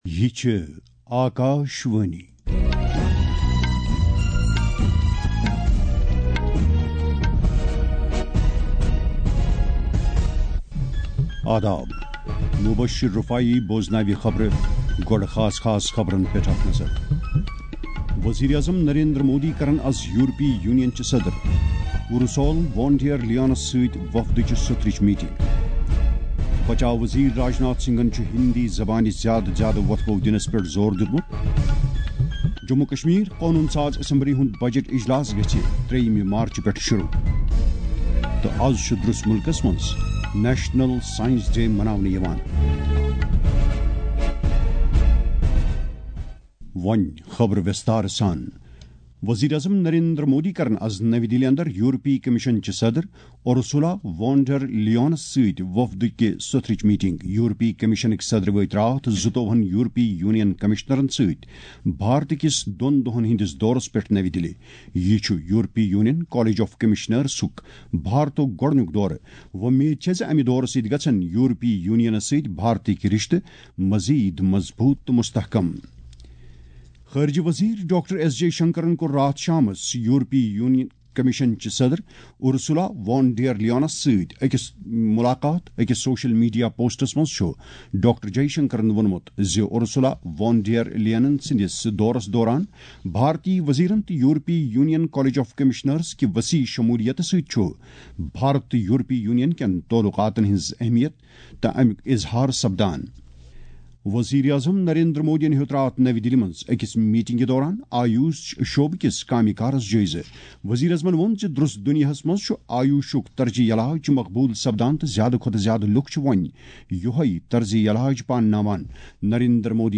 MORNING-NSD-KASHMIRI.mp3